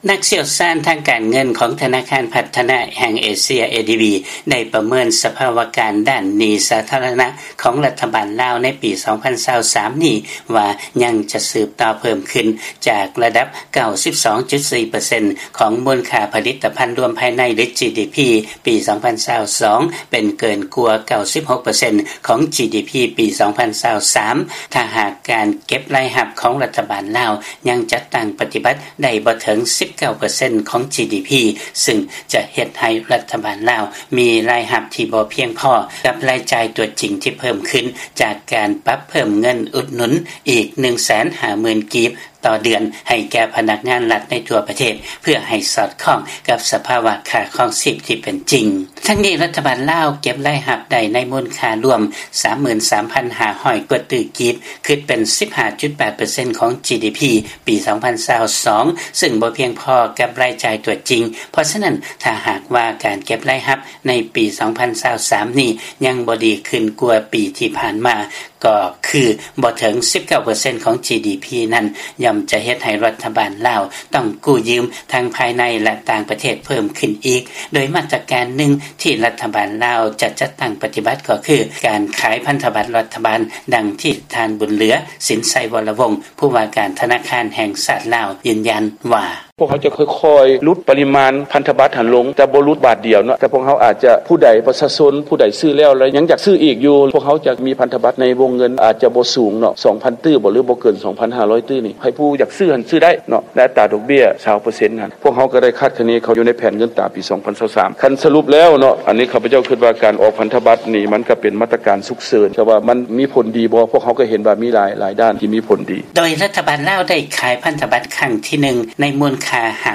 ມີລາຍງານຈາກບາງກອກ.